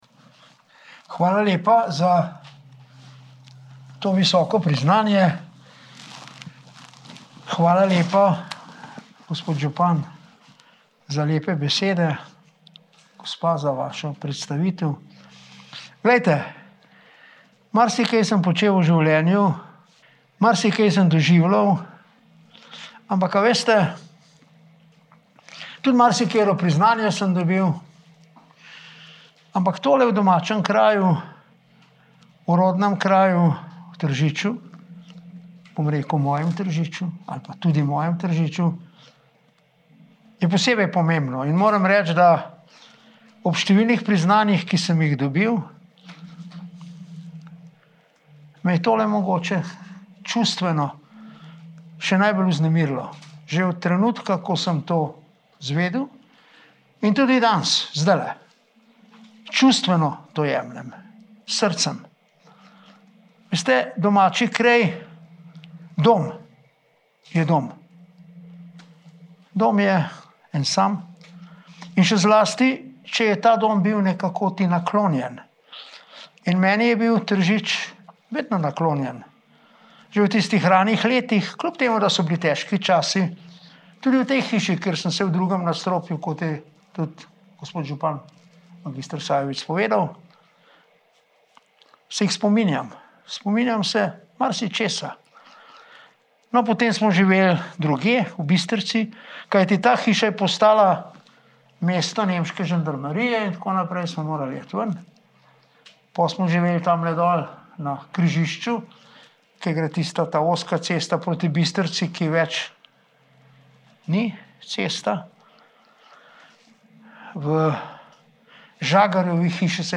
celotnaizjava_prof.dr.ernestpetric.mp3 (11MB)